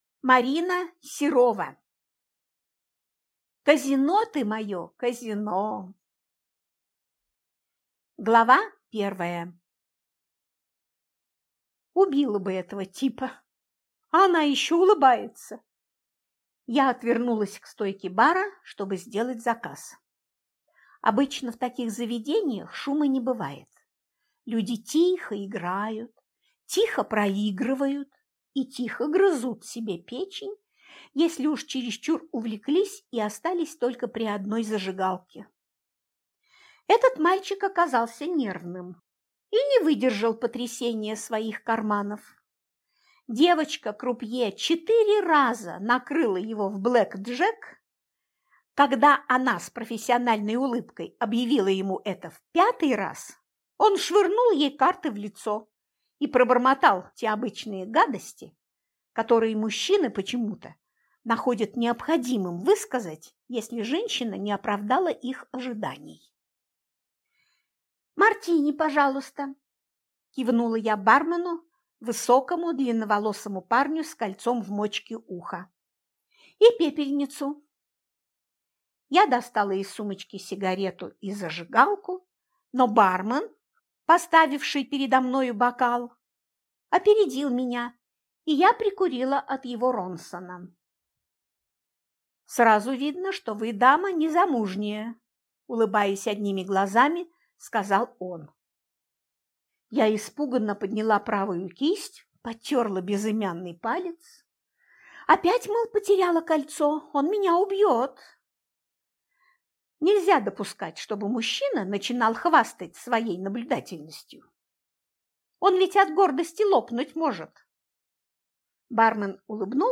Аудиокнига Казино ты мое, казино | Библиотека аудиокниг